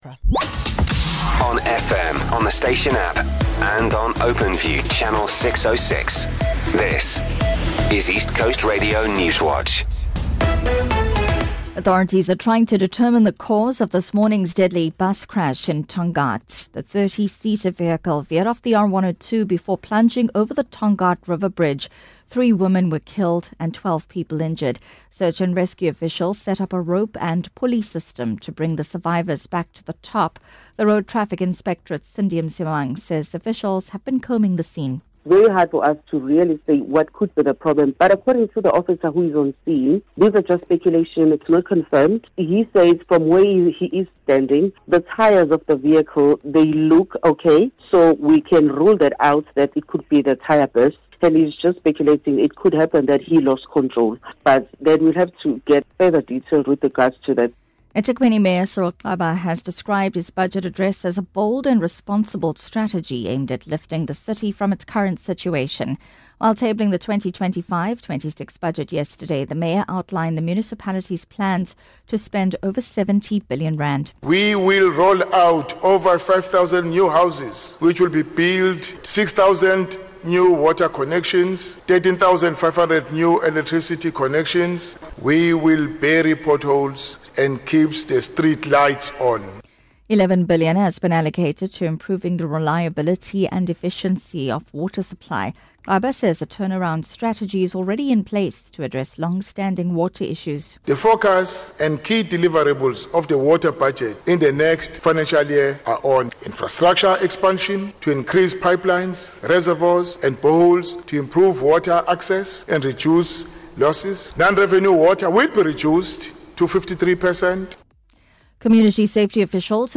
1 ECR Newswatch @ 11H00 3:32 Play Pause 5d ago 3:32 Play Pause Play later Play later Lists Like Liked 3:32 Here’s your latest ECR Newswatch bulletin from the team at East Coast Radio.